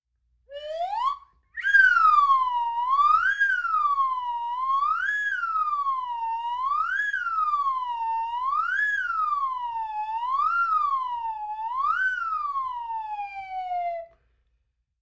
Звуки анимации
Струна гитары популярный эффект